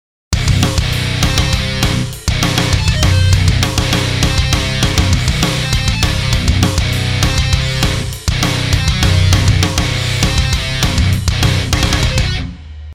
↓の画像はギターで、2・1・3・4、1・2・3・4＝4分の5拍子のリフです。
4分の5拍子のギターリフ
• 手（スネア・シンバル）で4分の4拍子を明示
• 足（バスドラ）はギターリフのリズムとシンクロ
4分の5拍子曲のドラムのサンプル